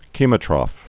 (kēmō-trŏf, -trōf)